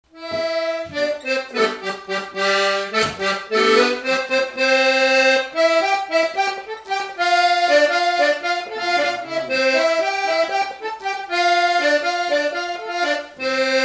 Rochetrejoux
Résumé instrumental
Usage d'après l'informateur gestuel : danse
Pièce musicale inédite